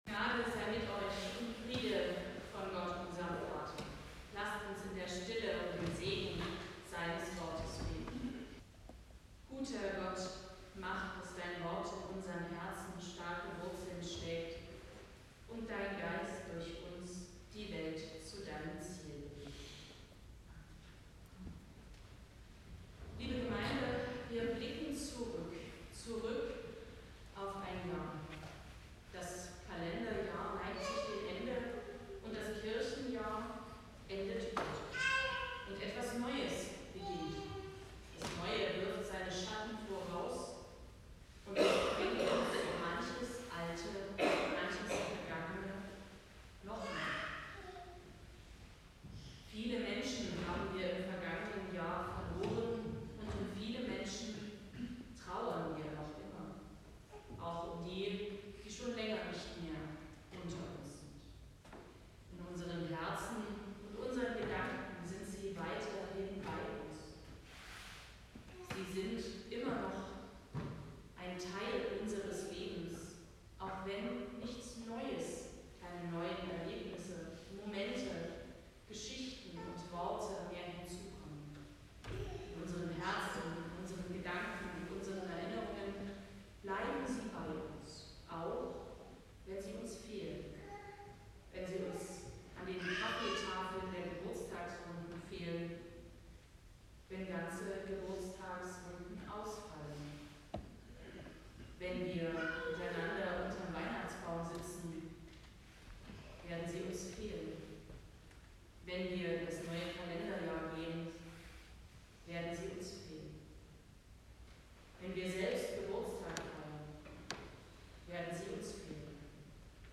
Passage: Psalm 126 Gottesdienstart: Predigtgottesdienst Stangengrün « Was sind gute Lebensfrüchte